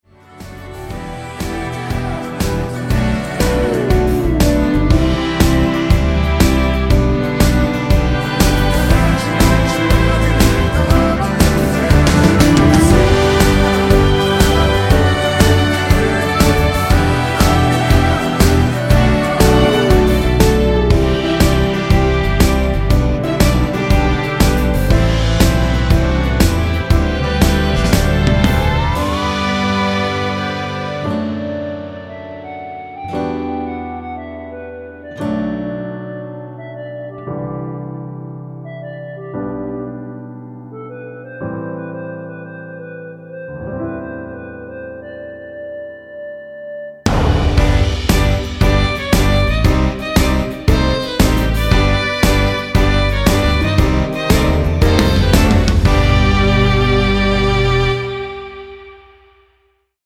엔딩이 길고 페이드 아웃이라서 노래끝나고 4마디 진행후 엔딩을 만들었습니다.(미리듣기 확인)
원키에서(-3)내린 멜로디와 코러스 포함된 MR입니다.(미리듣기 확인)
앞부분30초, 뒷부분30초씩 편집해서 올려 드리고 있습니다.
중간에 음이 끈어지고 다시 나오는 이유는